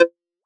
edm-perc-25.wav